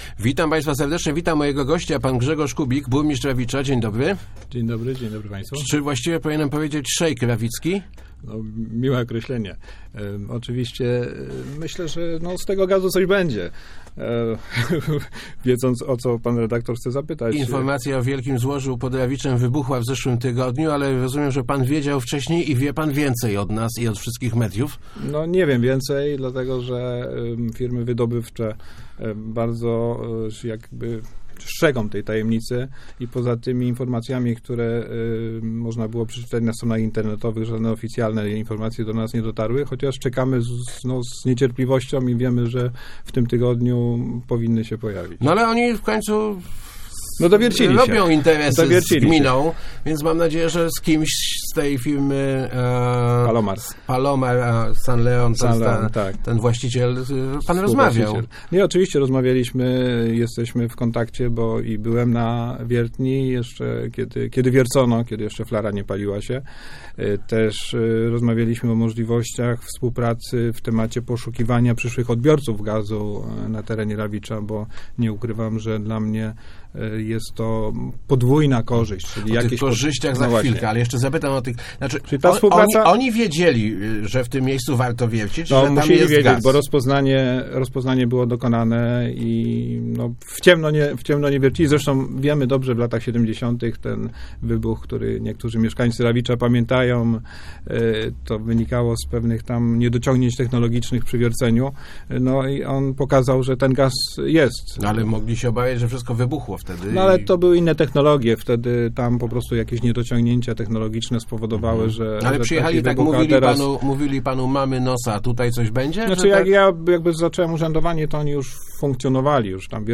Myślę, że dopiero pod koniec roku dowiemy się, na jakie pieniądze możemy liczyć z kopalni gazu - mówił w Rozmowach Elki burmistrz Rawicza Grzegorz Kubik. Informacje o dużym złożu pod miastem budzą duże apetyty, ale włodarz woli nie zapeszać.